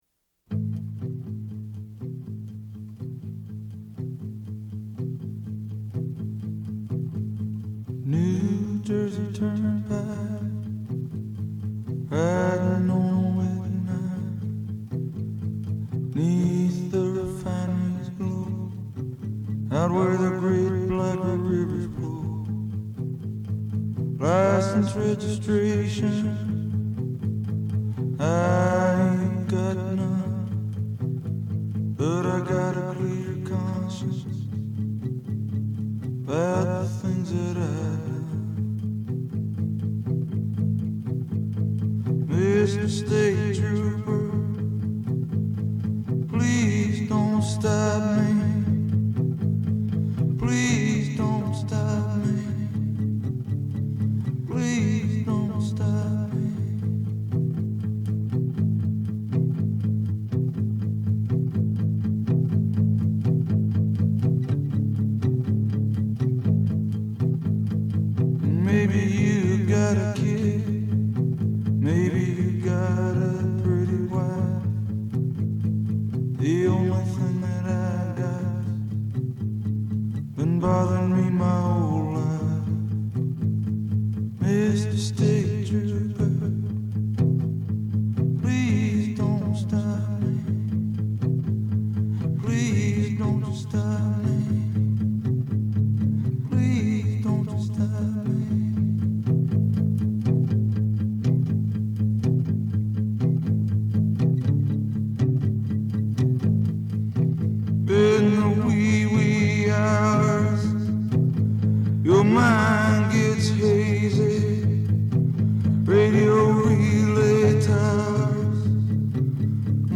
The stripped down, ghostly, folky